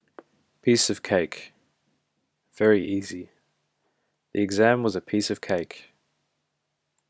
A piece of cake アメリカ、オーストラリアのネイティブ英語発音つき | 英会話の表現やイディオムを一日一分で学ぶ 一日一英会話 | マンツーマン英会話スクールのIHCWAY